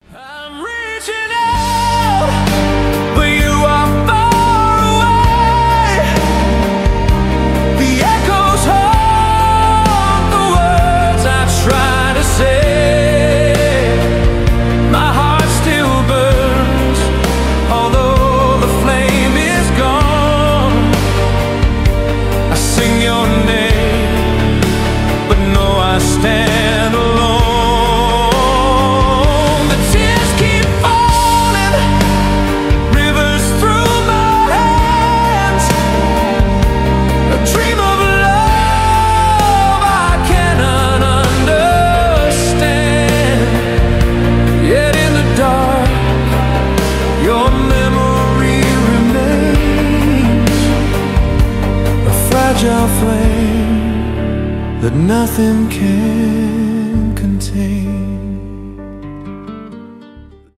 баллады
поп , рок